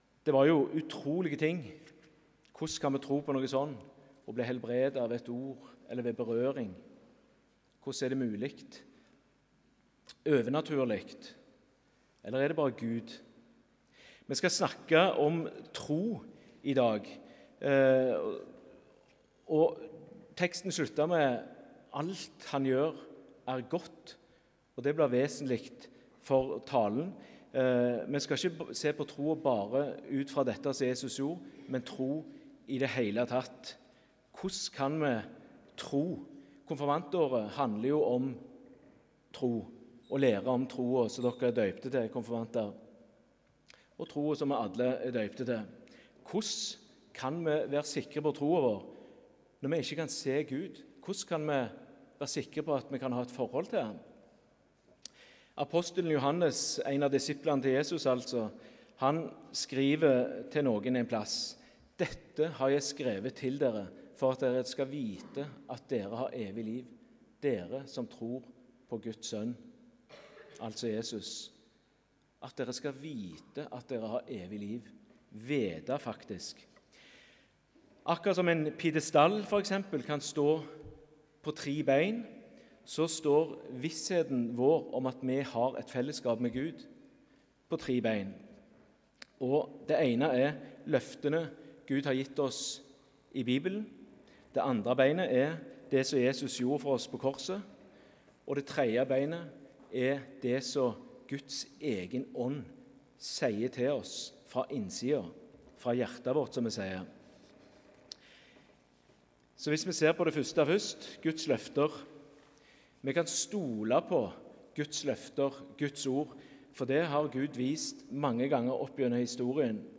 Tekstene Evangelietekst: Mark 7,31–37 Lesetekst 1: Rom 1,19–23 Utdrag fra talen (Hør hele talen HER ) Hvordan kan jeg være sikker på troen min?